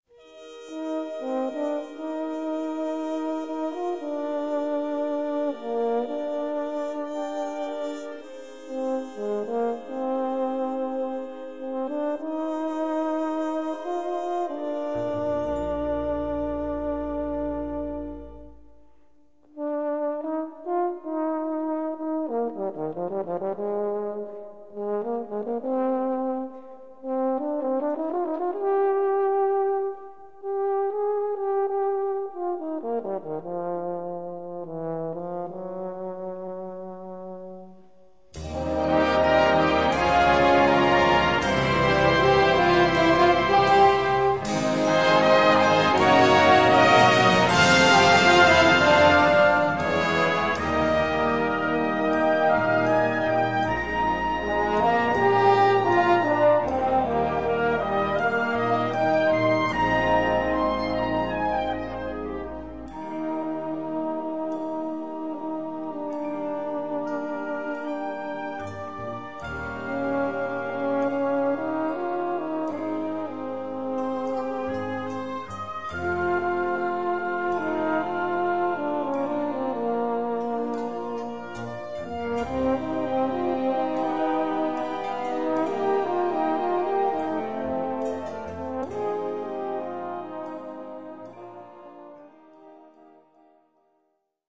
Gattung: Poptitel
Besetzung: Blasorchester